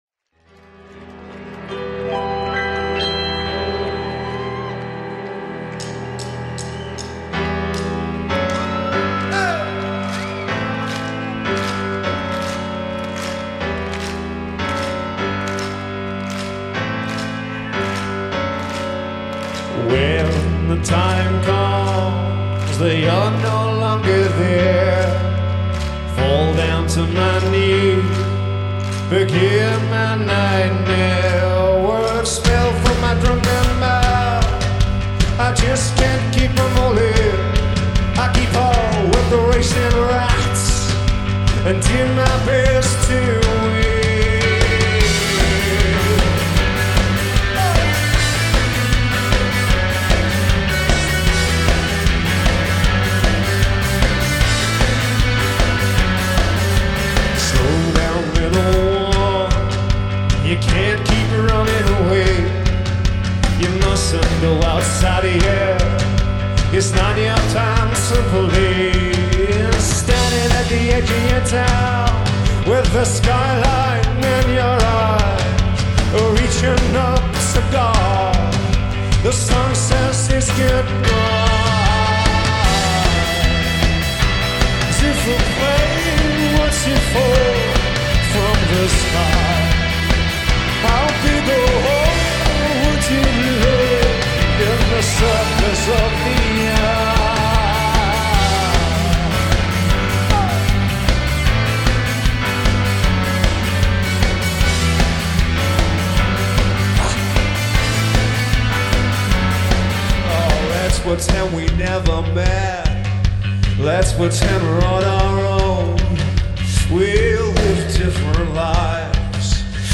lead vocals, guitar, piano
drums, percussion, backing vocals
Dark and moody
dark indie guitar rock